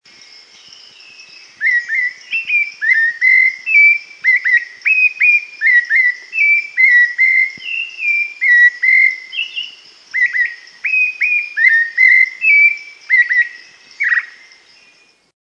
sabiá-laranjeira, na jaqueira da rampa da prefeitura o gaturamo marca presença; na Fundart é fácil ouvir o
Todos sons do centro da cidade de Ubatuba.